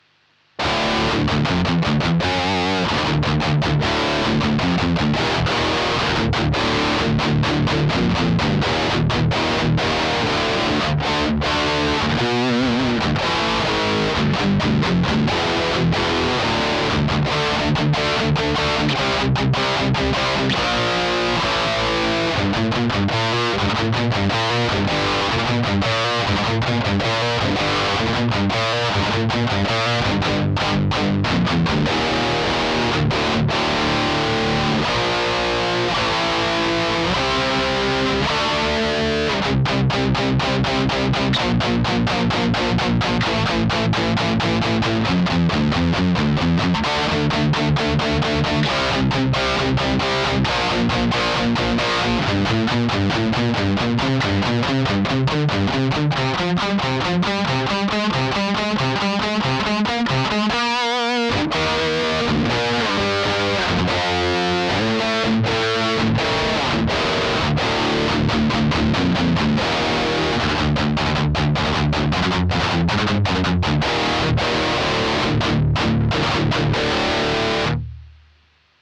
Ich habe ein wenig mit meiner Epiphone Tony Iommi SG in den Ditto-Looper eingespielt und das dann immer exakt so wiedergegeben.
Der Lead-Kanal war im High-Gain-Modus aktiviert und bei fast allen Aufnahmen ist der "Contour"-Schalter deaktiviert. Die Potis für Gain, EQ und Volume standen alle auf 12 Uhr.
Die Aufnahmen mit ihr "schwächeln" ein wenig.
Sie klingen eher hart und klar.